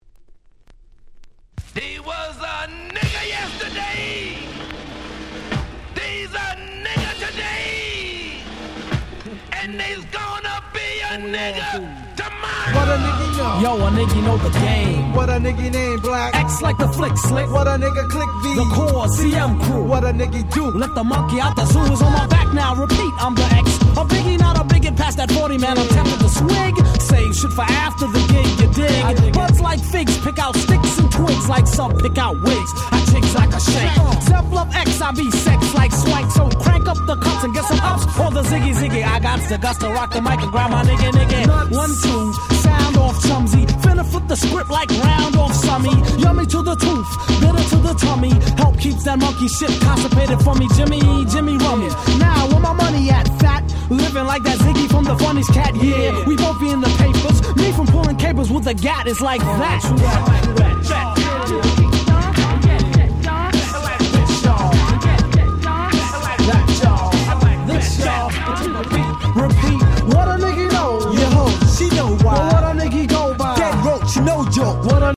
94' Very Nice Hip Hop !!
ケーエムディー New School ニュースクール 90's